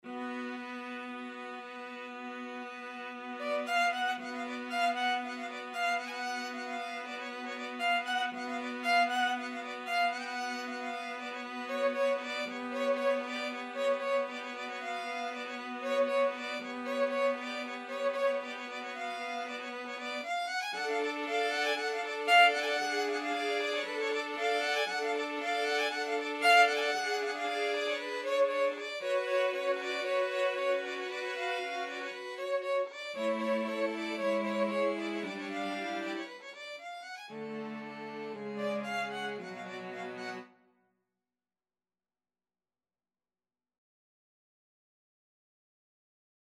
Violin 1Violin 2ViolaCello
2/4 (View more 2/4 Music)
Andante =116 =116
Classical (View more Classical String Quartet Music)